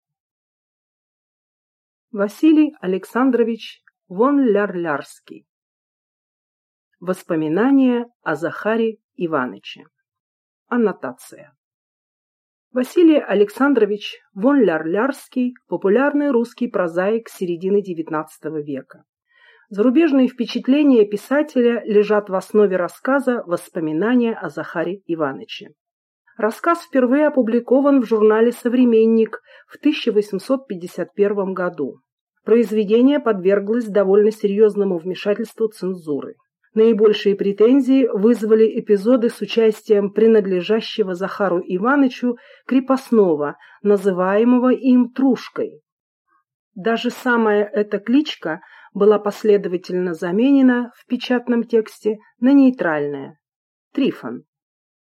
Аудиокнига Воспоминания о Захаре Иваныче | Библиотека аудиокниг